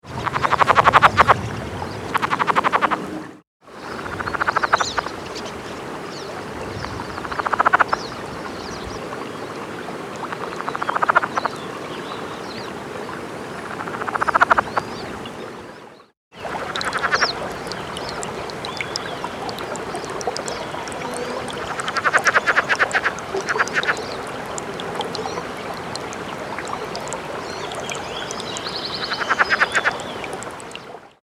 The following sounds were recorded on two sunny afternoons at the end of June and beginning of July at about 3,900 ft in Kittitas County, Washington.
Birds and trickling water are heard in the background.
Sounds This is a series of three different males calling edited into one 31 second recording. The calls of each frog have also been edited to remove long stretches of silence.